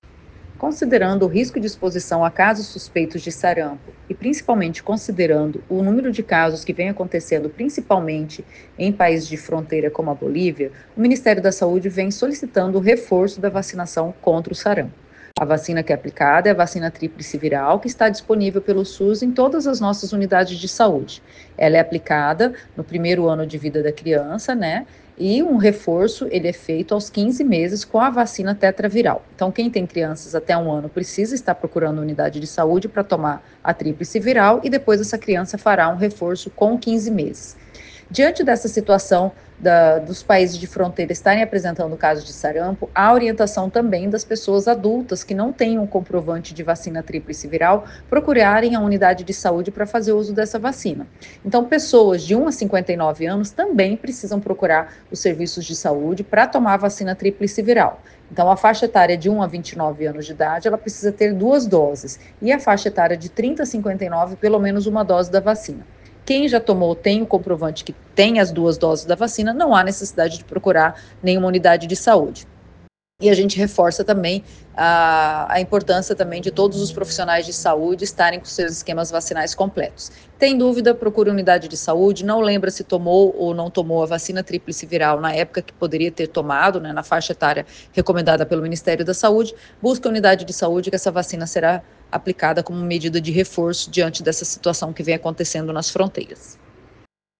em entrevista ao Agora 104.